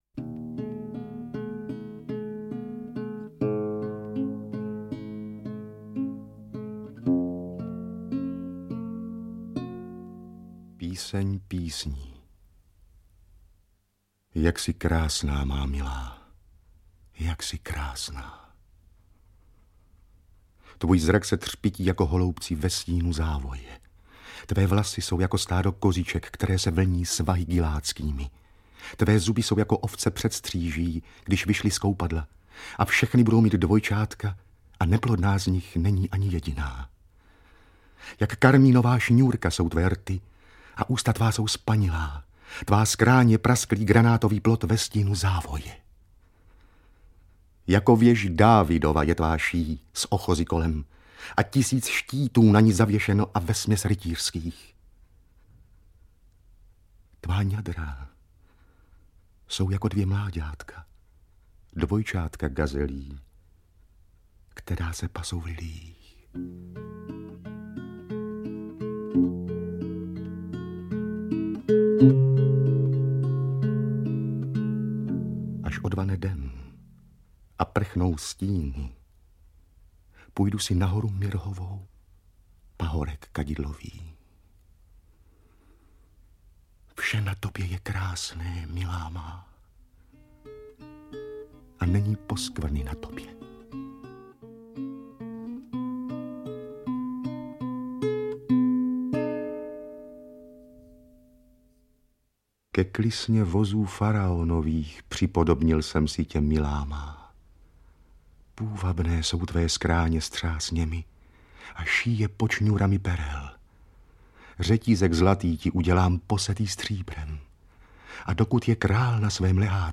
Interpreti:  Jiří Adamíra, Jaroslava Adamová, Vladimír Brabec, Otakar Brousek, Eduard Cupák, Irena Kačírková, Luděk Munzar, Vladimír Ráž, Vladimír Šmeral, Jiřina Švorcová, Josef Větrovec, František Vicena, Václav Voska
beletrie / poezie / česká poezie